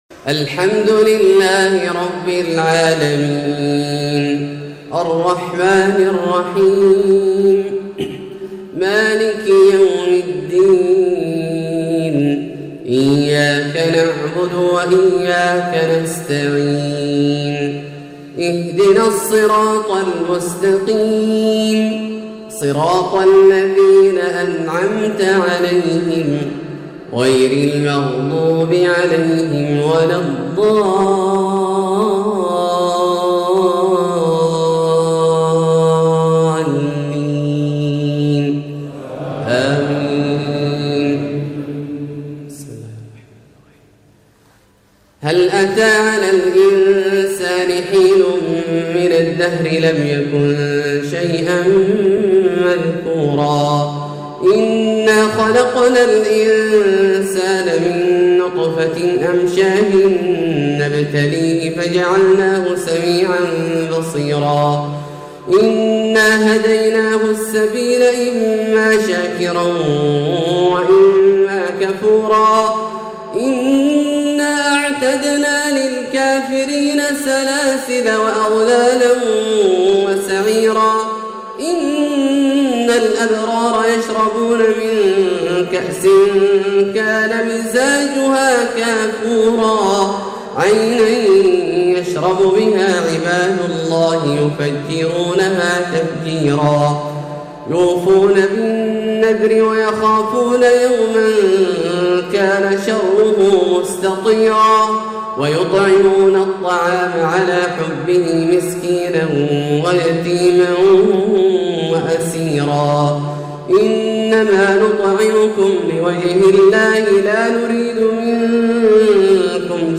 أجمل مارتل الشيخ عبدالله الجهني في الكويت > زيارةالشيخ عبدالله الجهني للكويت شهر 7 عام 1439 هـ > المزيد - تلاوات عبدالله الجهني